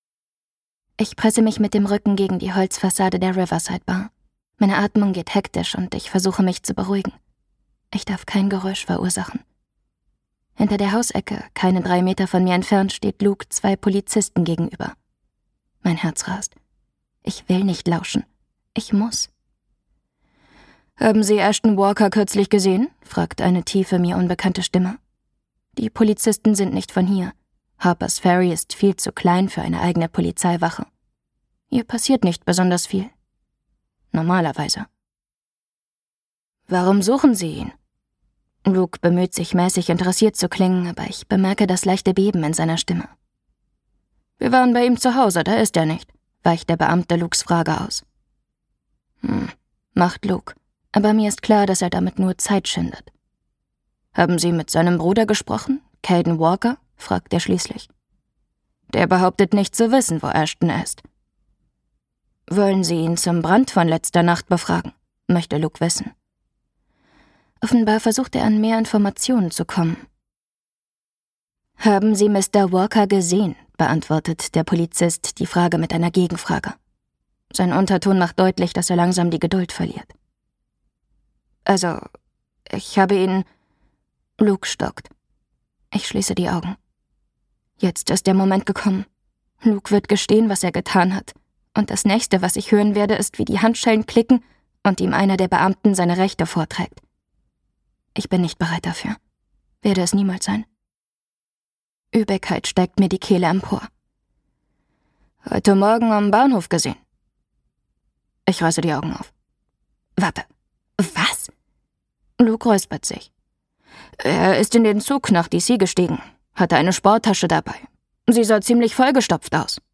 Gekürzt Autorisierte, d.h. von Autor:innen und / oder Verlagen freigegebene, bearbeitete Fassung.
Hörbuchcover von Harpers Ferry. Lose Me Once